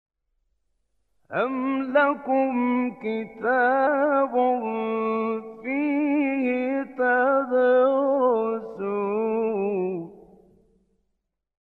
گروه شبکه اجتماعی: مقاطعی صوتی از راغب مصطفی غلوش که در مقام حسینی اجرا شده است، می‌شنوید.
این مقاطع که در مقام حسینی اجرا شده‌اند، در زیر ارائه می‌شوند.